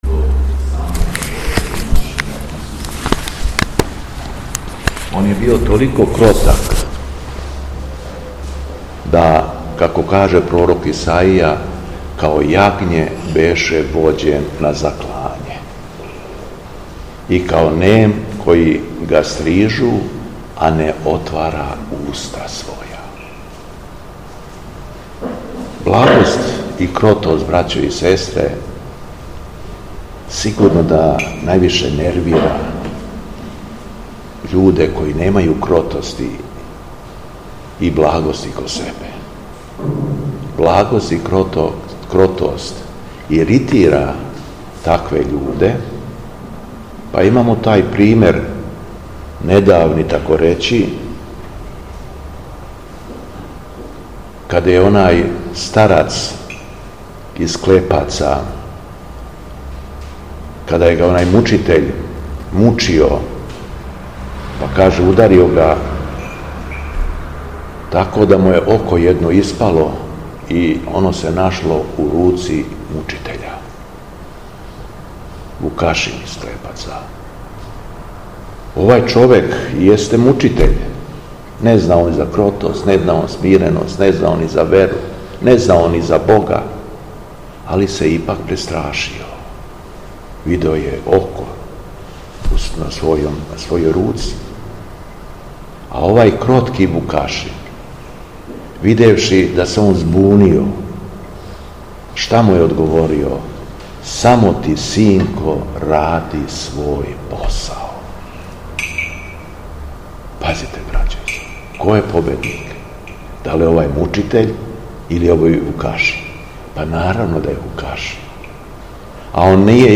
Беседа Његовог Преосвештенства Епископа шумадијског г. Јована
Након прочитаног јеванђеља по Марку, епископ Јован се обратио верном народу следећим речима: